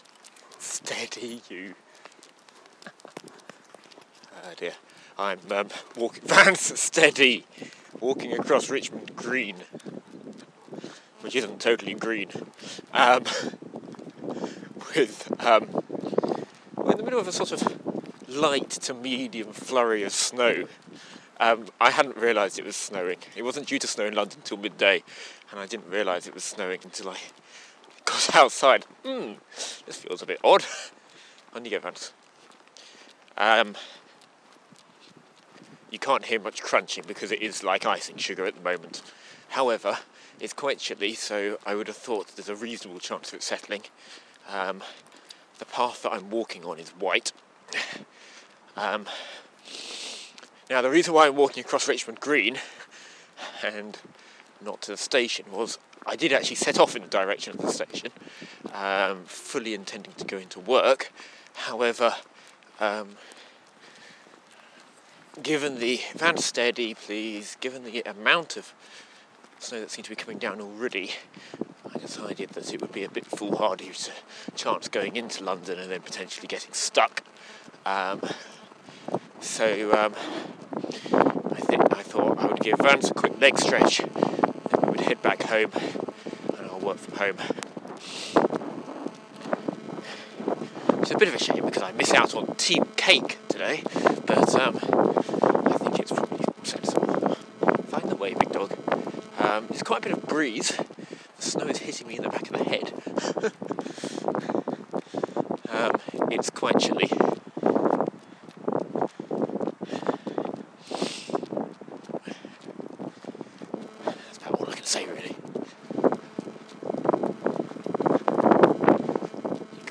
Snow starting to fall in Richmond